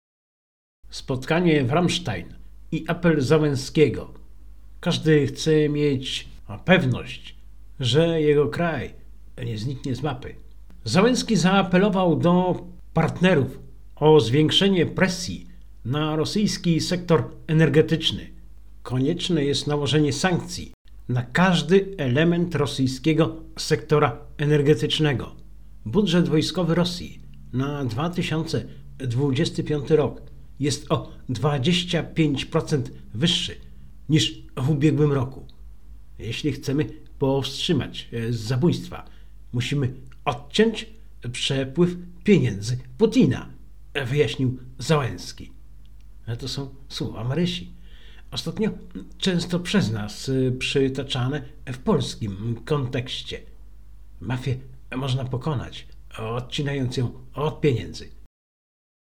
W Radiu Maria od początku pełnoskalowego konfliktu w Ukrainie, w samo południe nadajemy audycję „Pół godziny dla Ukrainy”. Stałym elementem jest hymn Ukrainy, gdzie w tle słychać odgłosy walk i informacje z frontu.